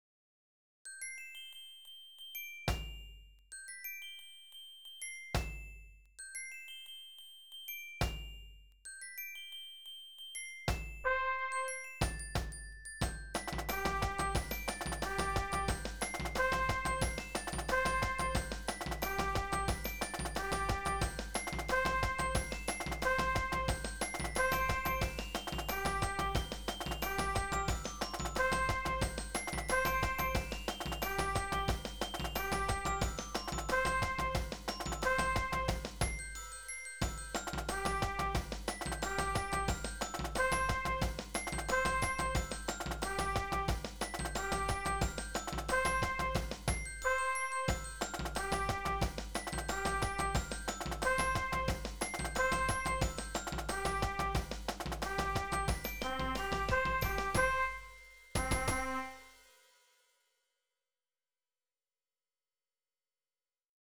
Música para banda